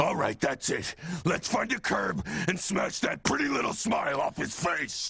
Play, download and share Curb Stomp original sound button!!!!
curb-stomp.mp3